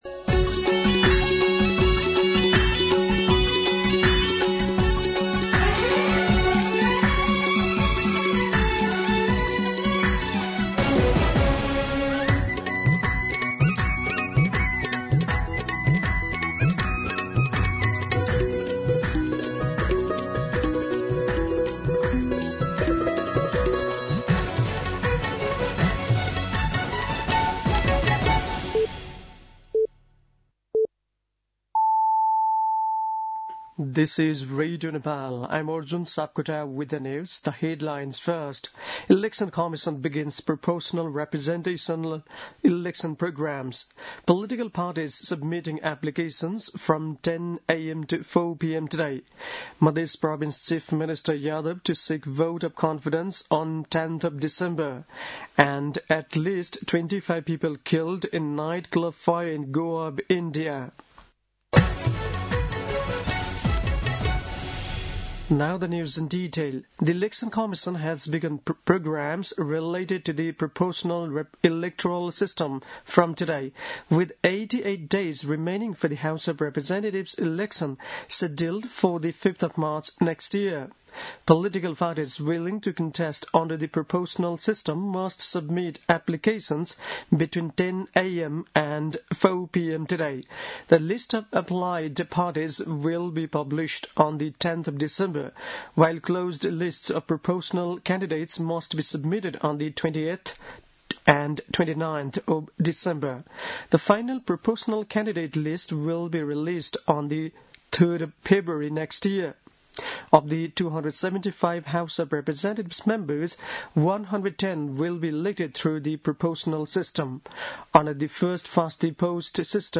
दिउँसो २ बजेको अङ्ग्रेजी समाचार : २१ मंसिर , २०८२